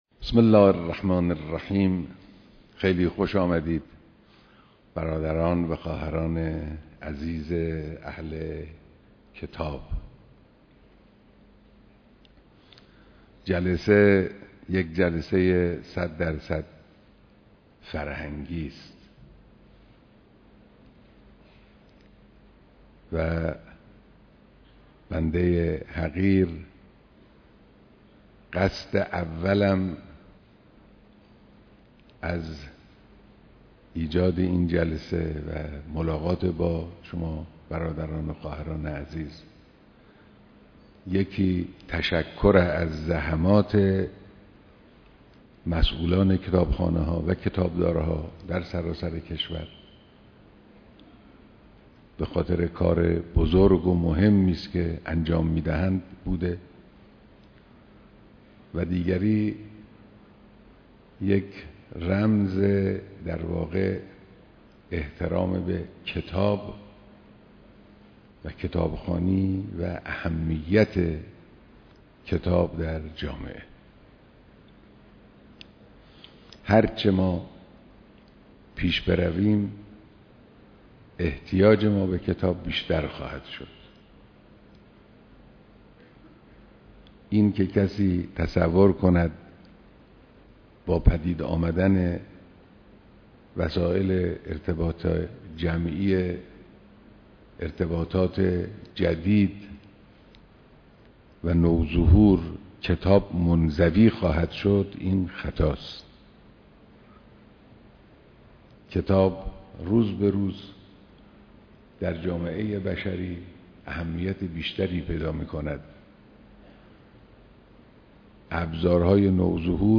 بیانات در دیدار مسئولان كتابخانه‌ها و كتابداران